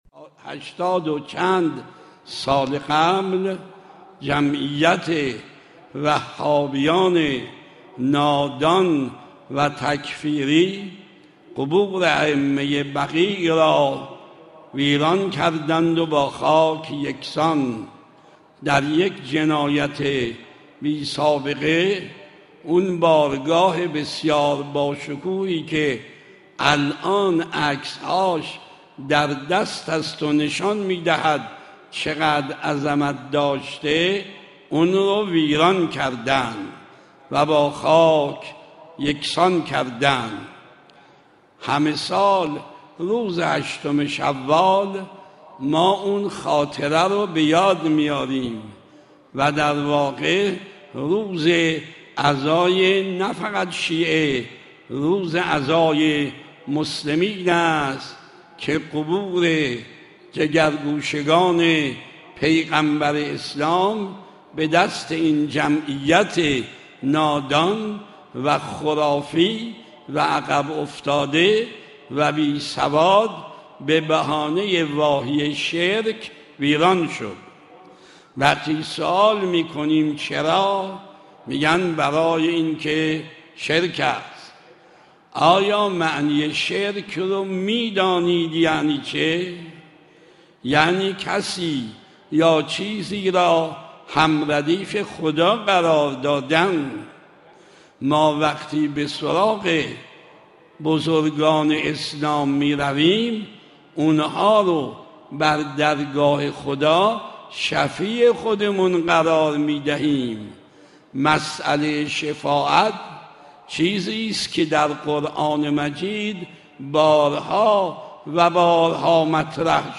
فرازی از سخنان آیت الله مکارم شیرازی در حرم مطهر رضوی